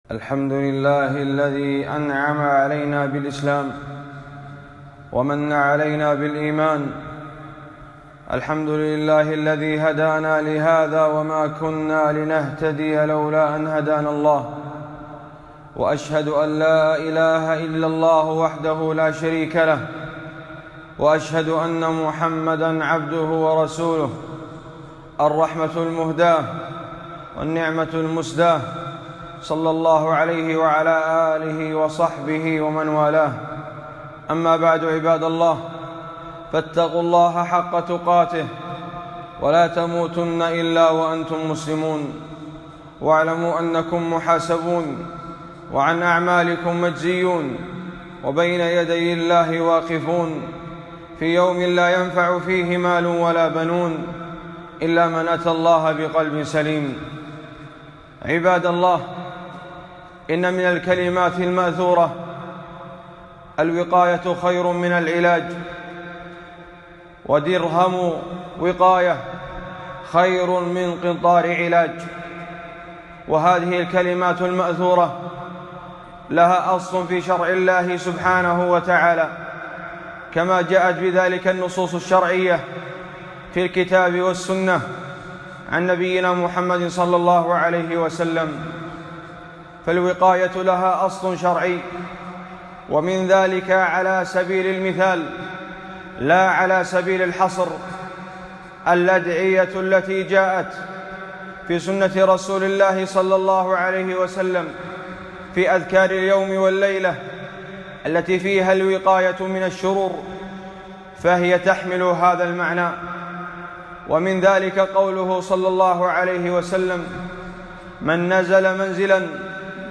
خطبة - الوقاية خير من العلاج والتحذير من الشائعات